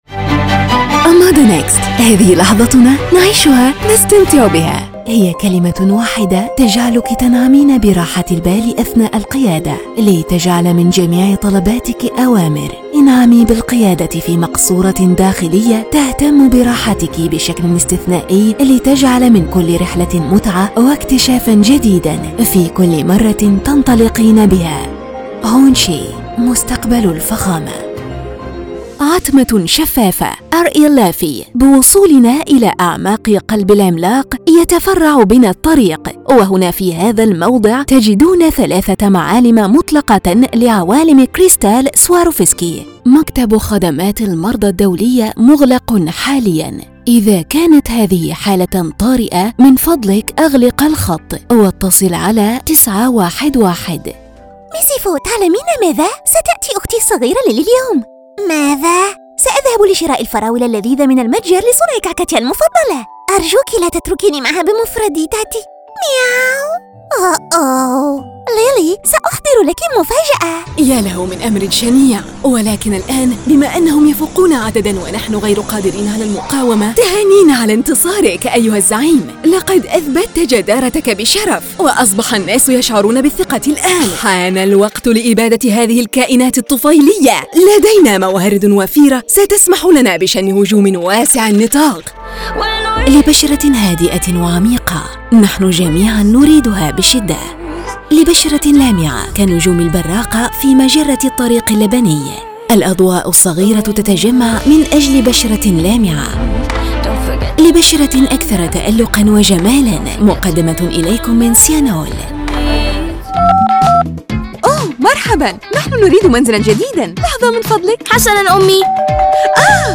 A friendly tenor voice performer in LATAM neutral, Mexican accent and English with Hispanic accent. Listen to an authentic, confident, and energetic voice ready for your projects....